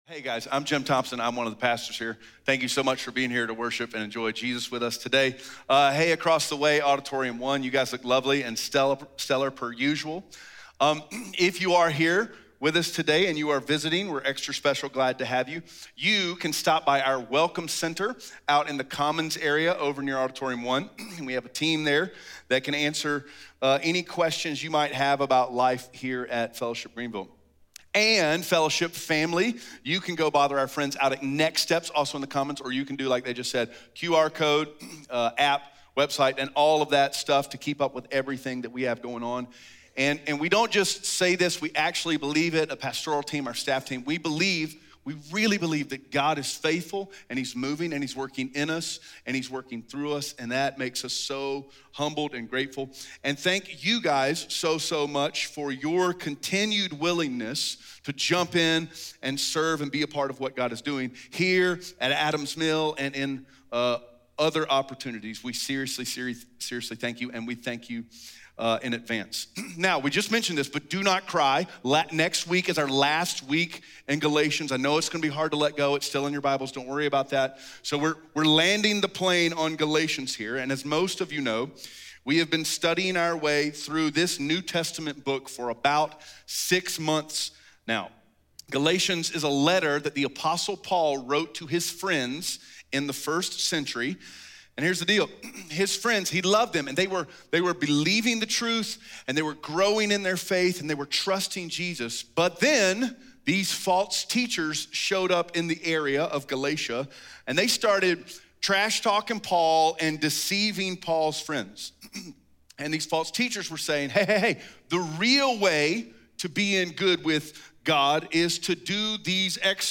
Galatians 6:11-14 Audio Sermon Notes (PDF) Ask a Question SERMON SUMMARY When you wake up in the morning, why do you dress the way you dress?